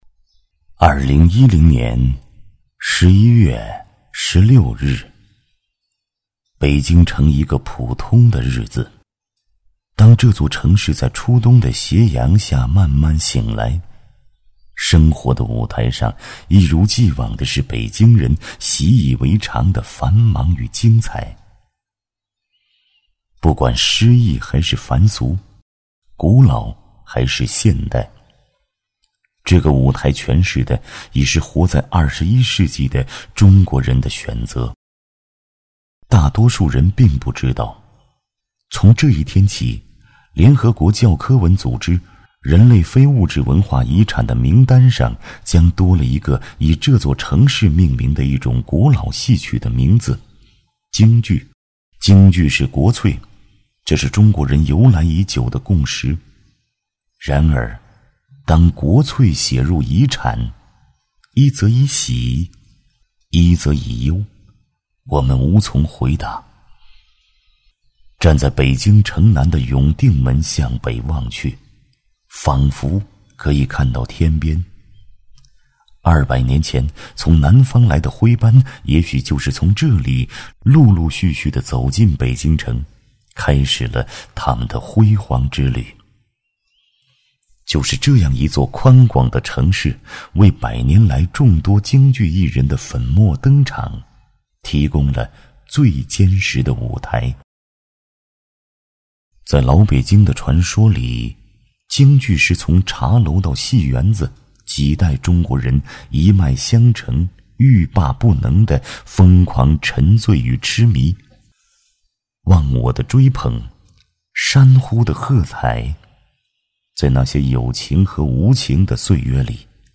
擅长：专题片 广告
特点：大气浑厚 稳重磁性 激情力度 成熟厚重